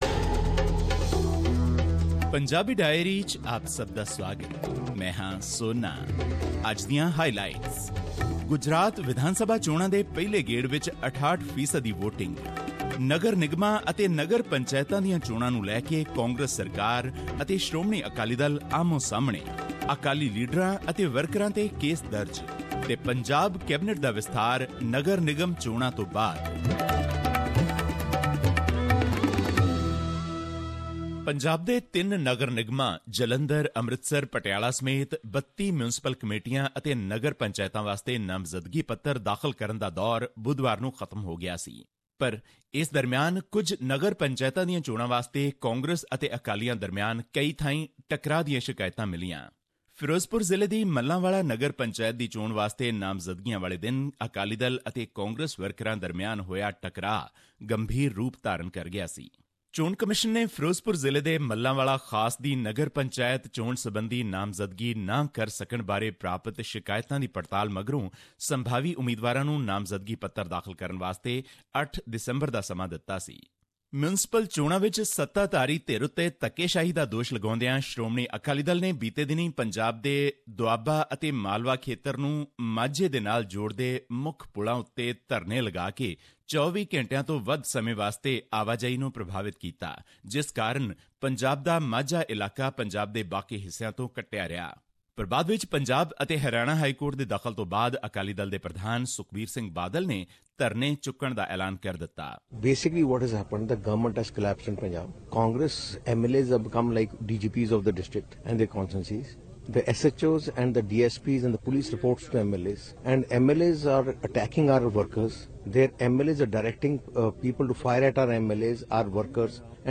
Here is our weekly Punjabi Diary. This report was presented on SBS Punjabi program on Dec 11, 2017, which touched upon issues of Punjabi and national significance in India.